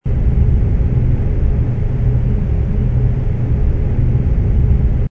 ambience_base_space.wav